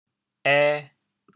ê2.mp3